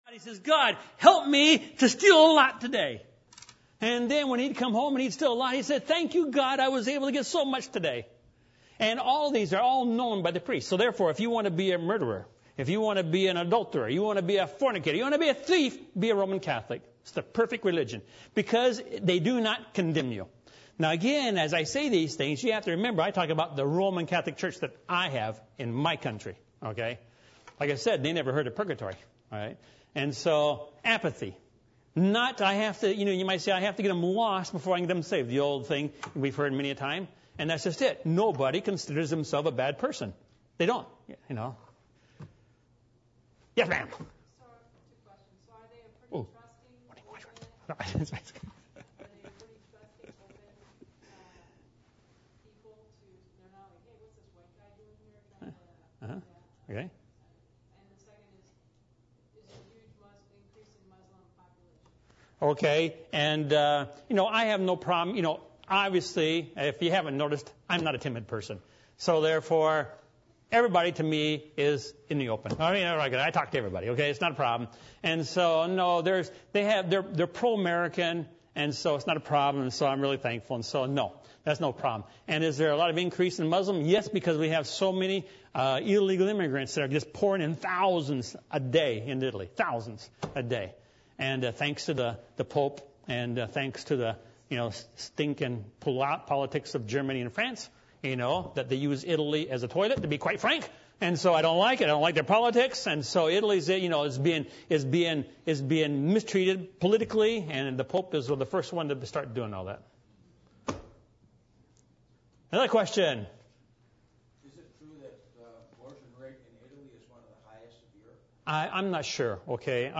Ephesians 4:1-32 Service Type: Midweek Meeting %todo_render% « The Blessing Of The Lord The Gospel